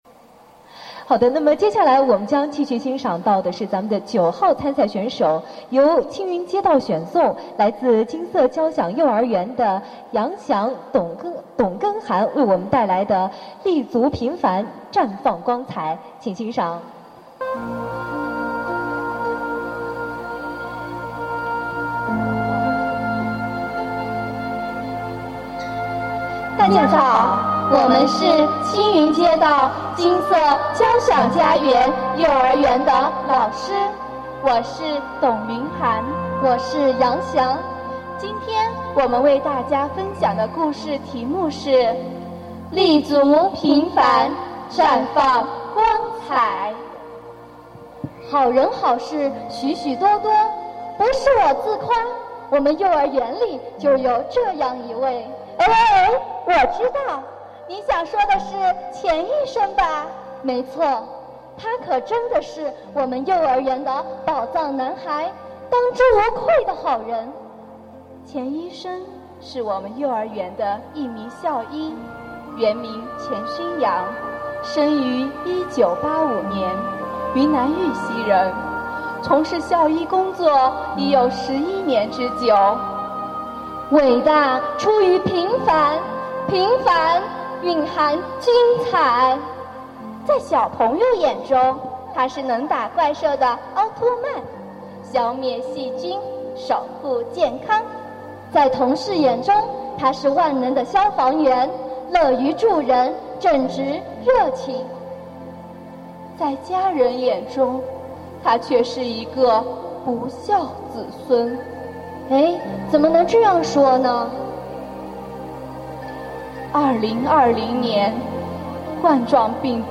2020年“书香盘龙”全民阅读系列活动——第三届“盘龙江畔话好人”讲故事比赛（决赛）获奖选手音频展播 | 昆明少年儿童图书馆
为把每一个“好人故事”讲给所有人听，我们专门为每一个参赛选手录制了音频，每天我馆都将在微信平台为您展播一位优秀选手带来的好人故事。